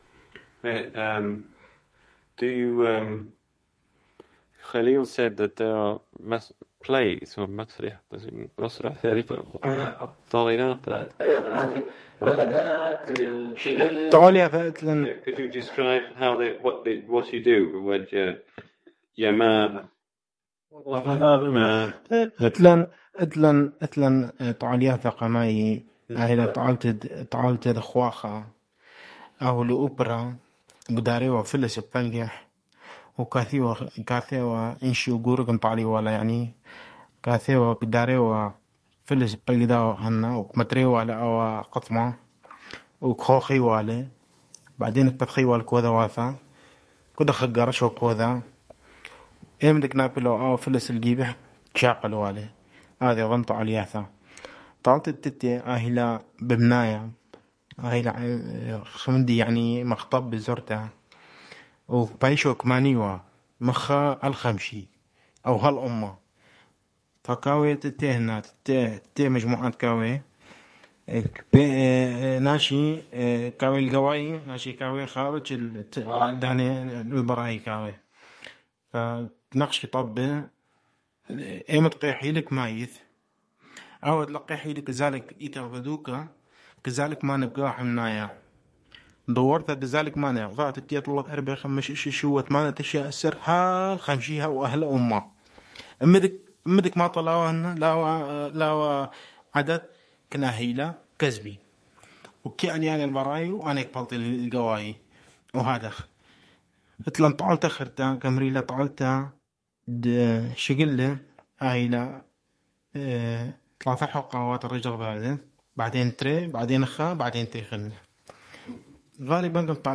Qaraqosh (Baghdede): Games